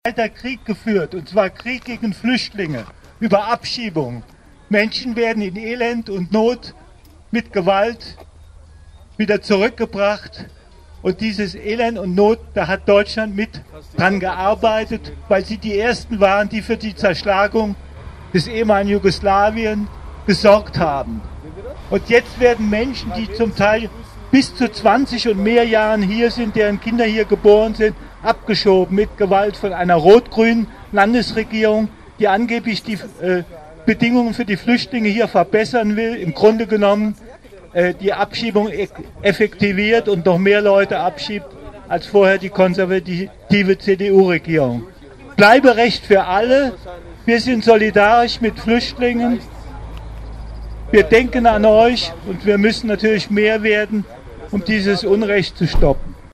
Ein kurzes Statement am Zaun des Deportation Airparks .